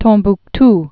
(tōɴbk-t)